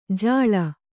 ♪ jāḷa